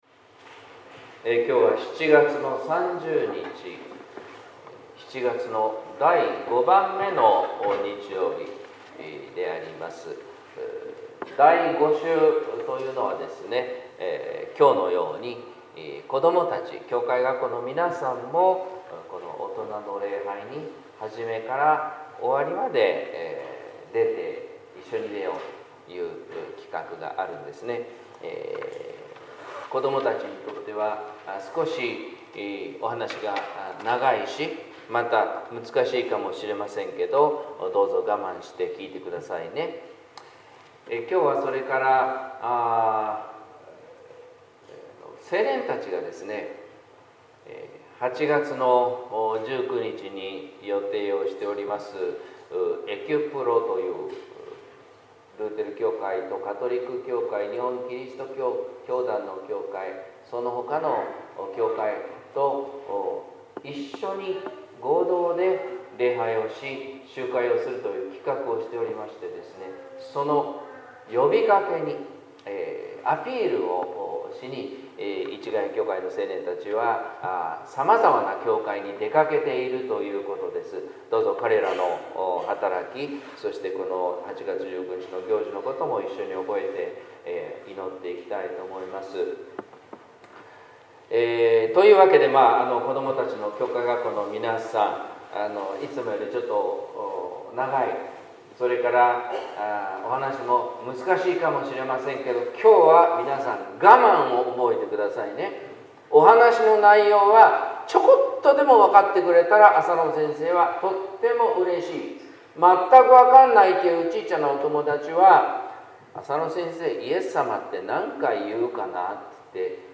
説教「負いやすい軛」（音声版） | 日本福音ルーテル市ヶ谷教会